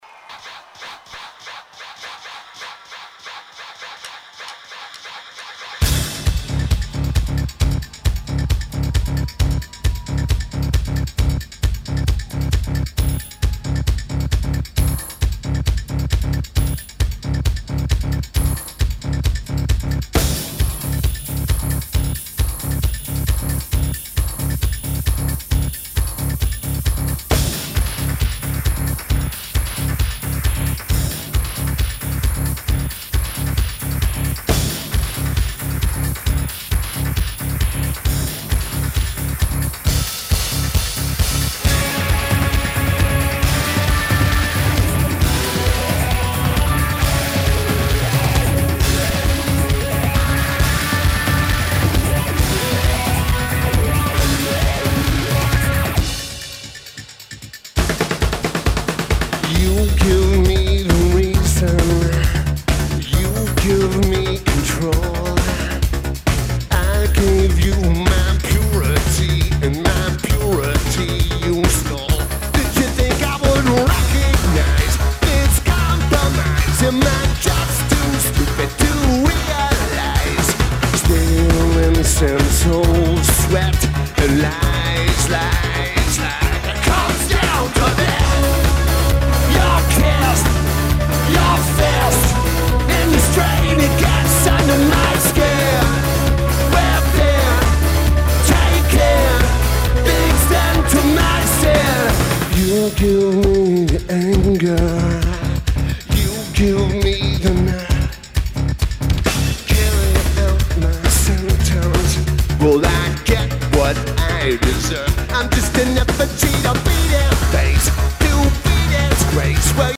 Saenger Theatre
Lineage: Audio - IEM (Feed > Tascam DR-2D)